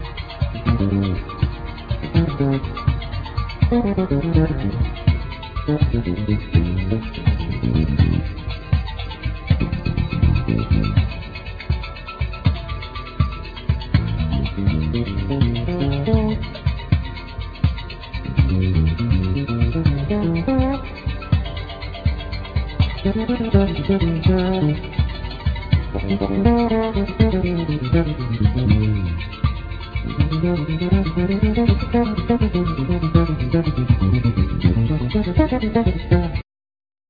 Saxophone
Keyboards
Guitars
Bass
Percussions
Drums
Vocals